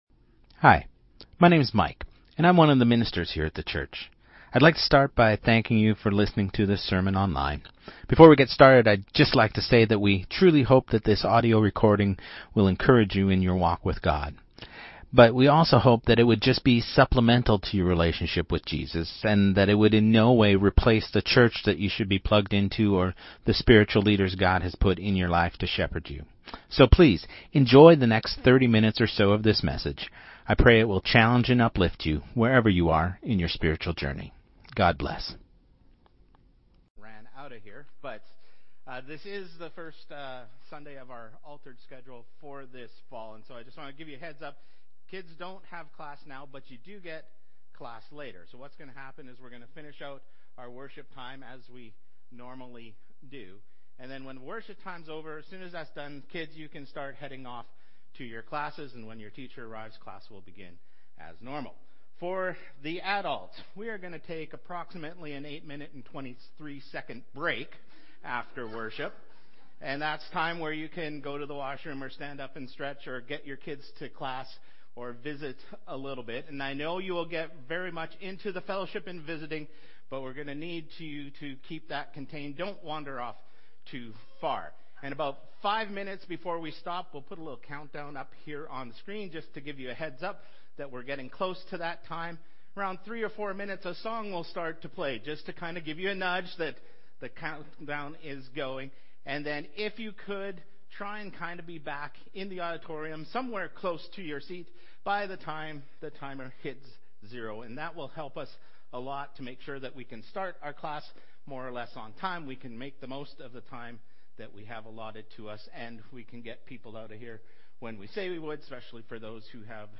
Sermon Series | Church of Christ Saskatoon